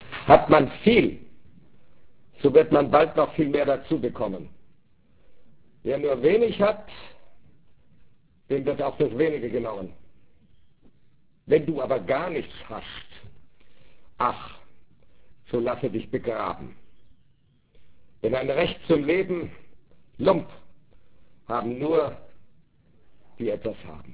Heinrich Heine Jahr Düsseldorf 1997 - Karlheinz Böhm liest Heinrich Heine - Aufzeichnungen vom 1. Dezember 1996 im Heinrich-Heine-Institut Düsseldorf